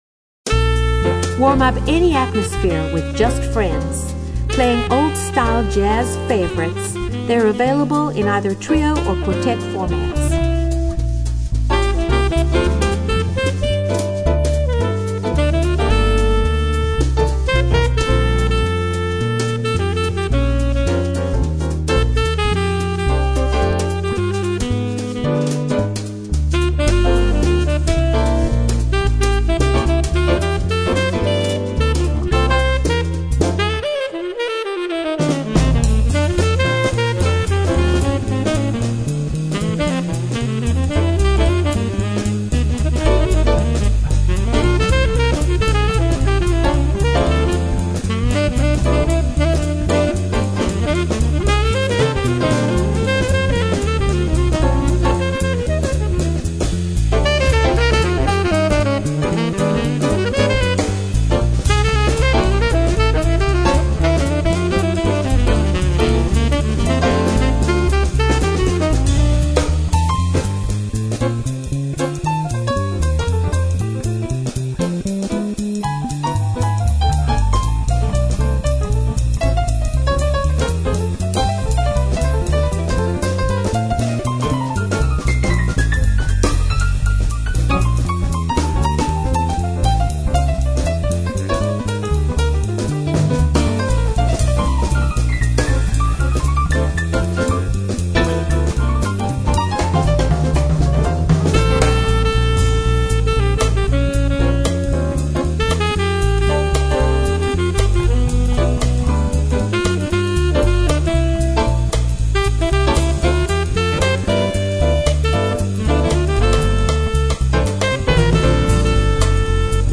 the music is always warm and sophisticated.